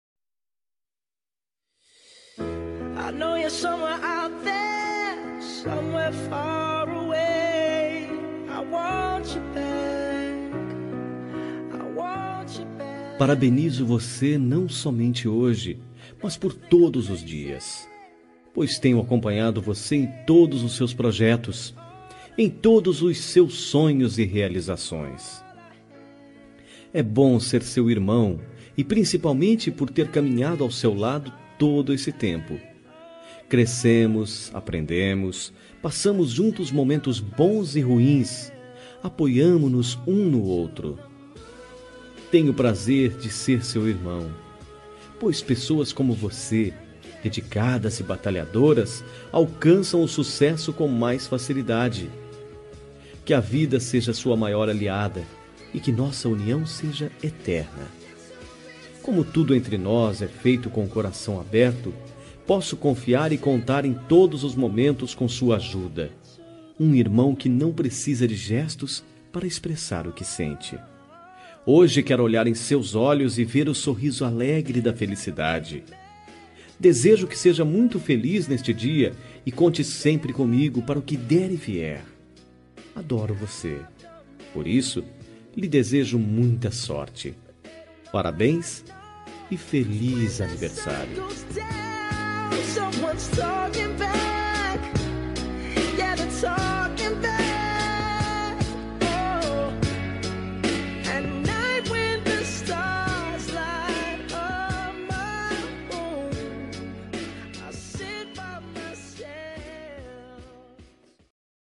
Telemensagem de Aniversário de Irmão – Voz Masculina – Cód: 4029